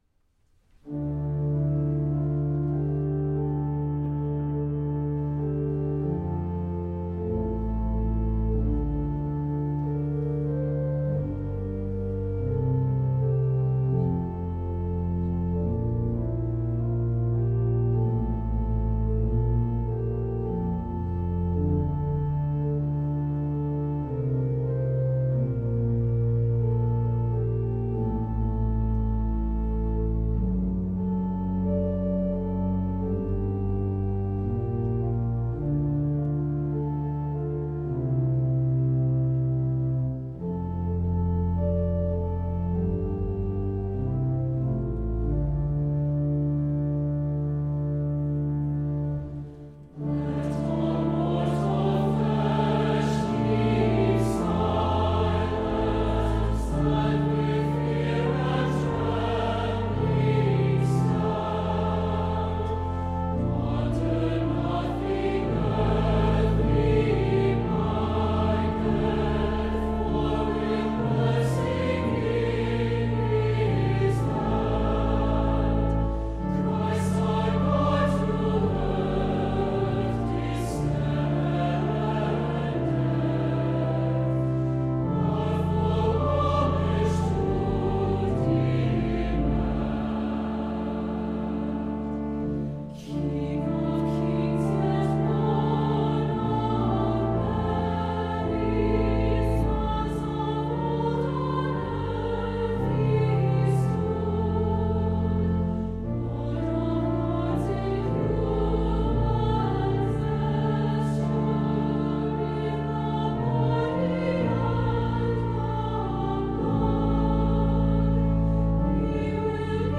曲调采用的小调式贴切地诠释了歌词中所表达的敬畏和威严感。